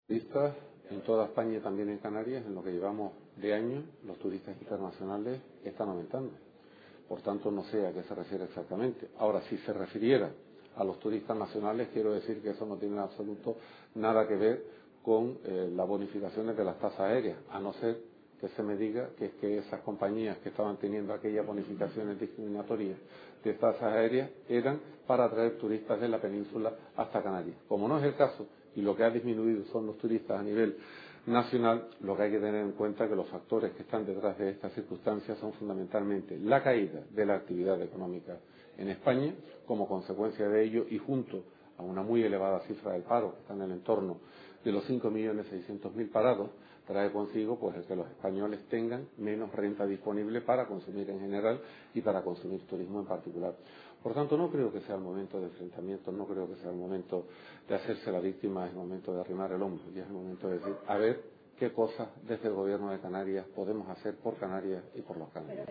Estas son las declaraciones del ministro José Manuel Soria realizadas en el marco del III Encuentro Mapfre-Garrigues "Necesitamos más Europa".